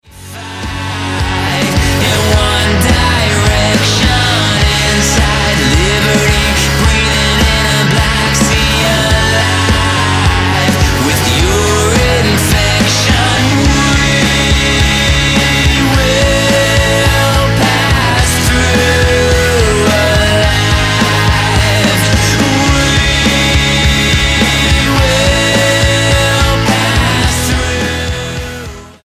A rock band from Nova Scotia